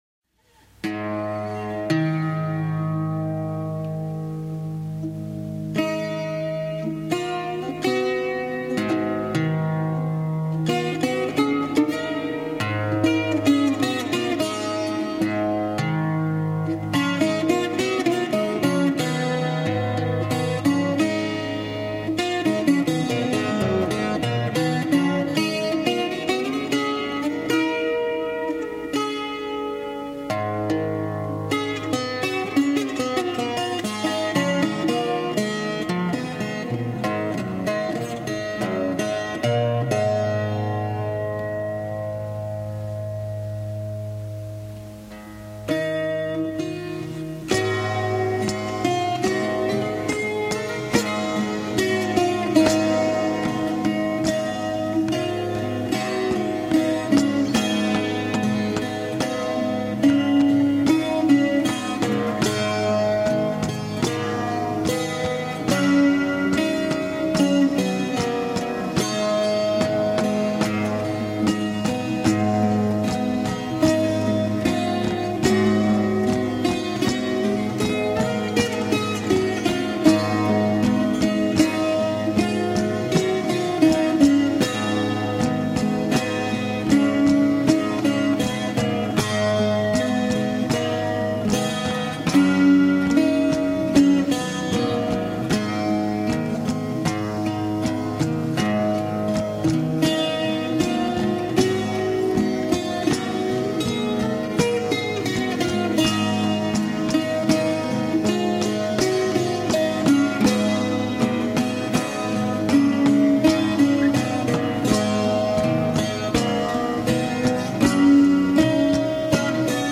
Classic 15th Century Italian piece.
Folk